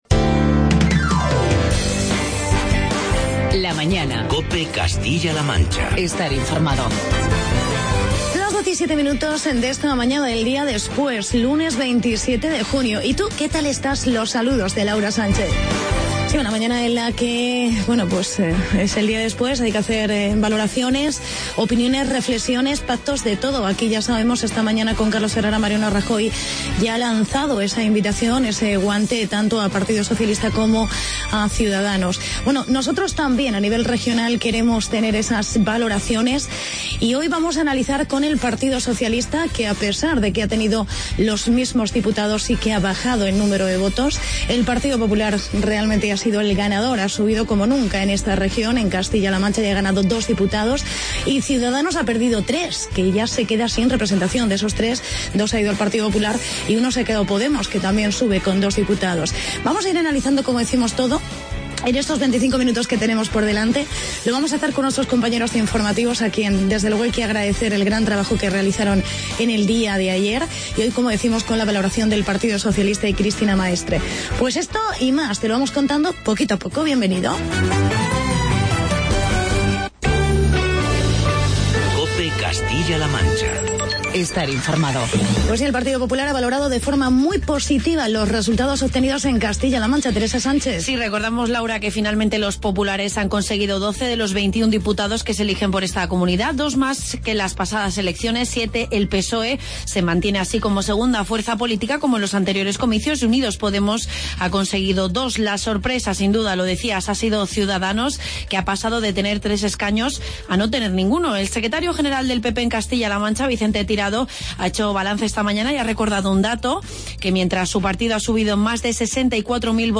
Entrevista a la portavoz del Psoe en CLM, Cristina Maestre.